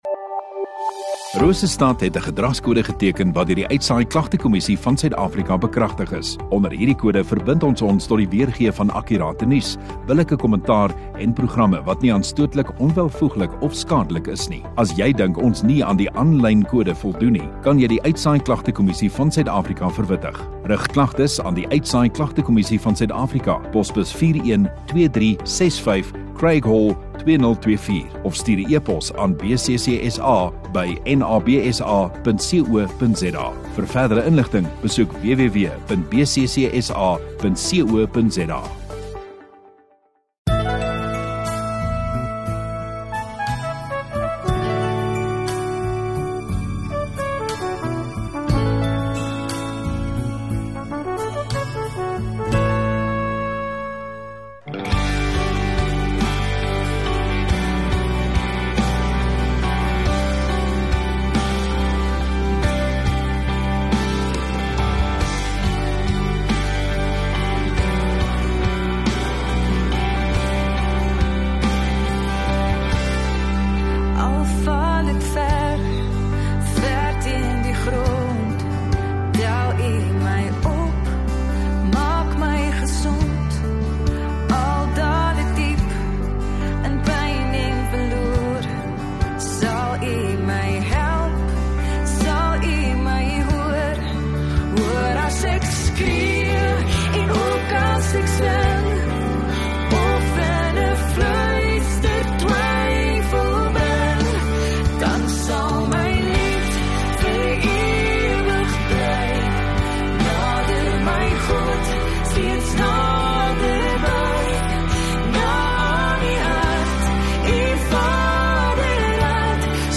22 Feb Sondagaand Erediens